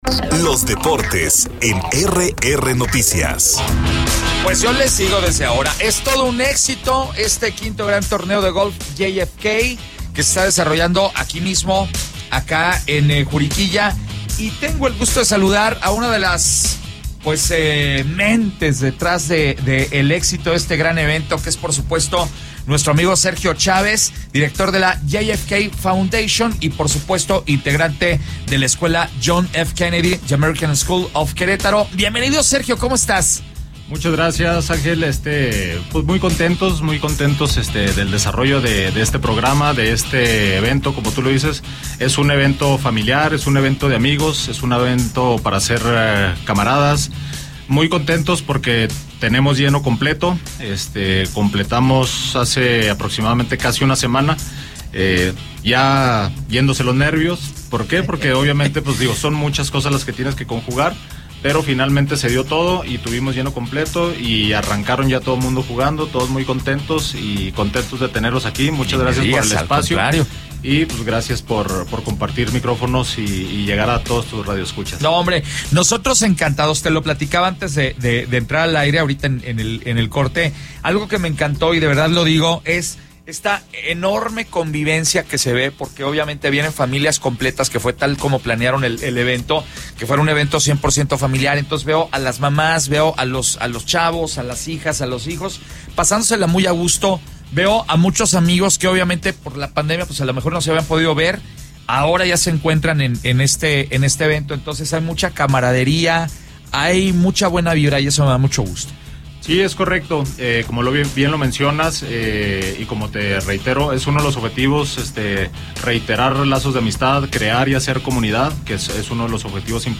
EntrevistasOpiniónPodcast